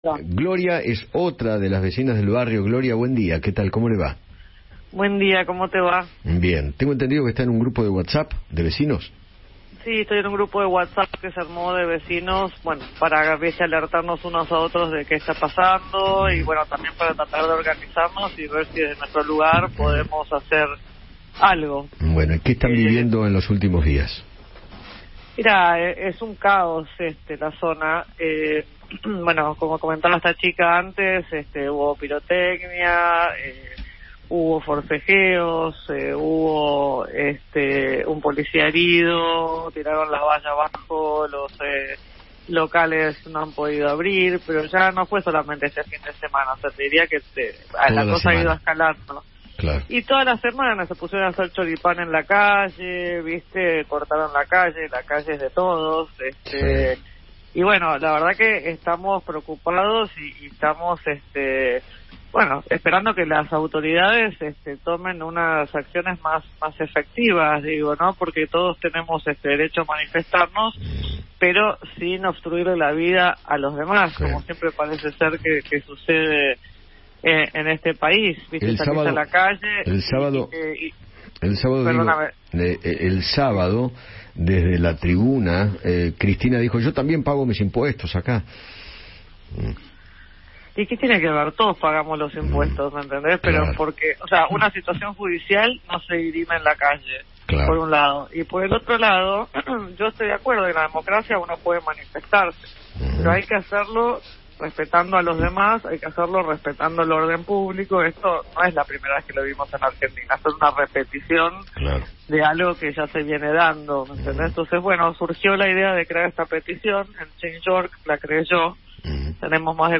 Eduardo Feinmann habló con dos vecinas de la zona de Recoleta, quienes se vieron afectadas por las manifestaciones en apoyo a Cristina Kirchner, y relataron la vandalización de propiedades o agravios verbales que sufrieron el sábado.